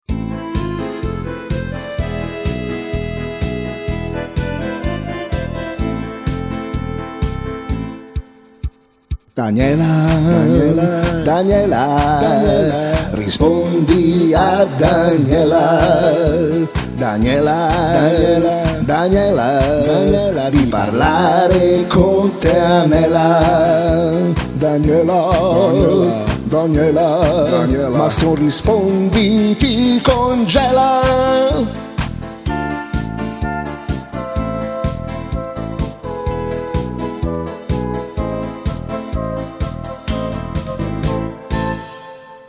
Una suoneria personalizzata che canta il nome